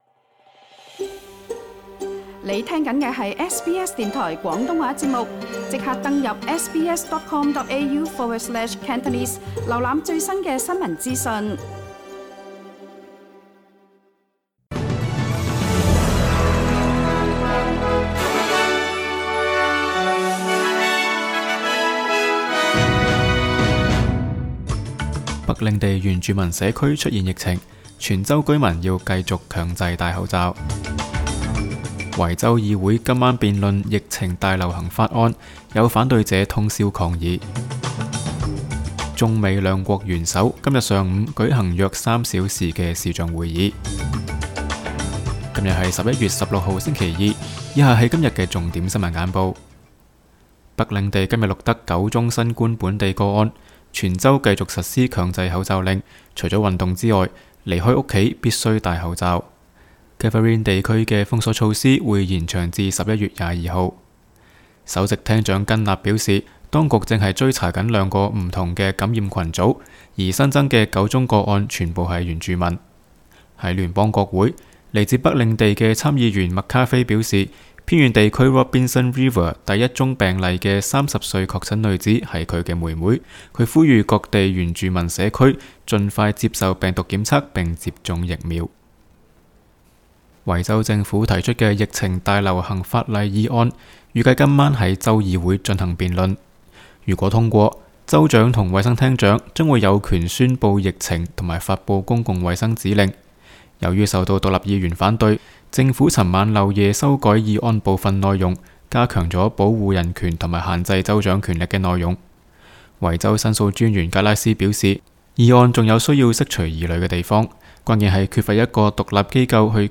SBS 廣東話節目新聞簡報 Source: SBS Cantonese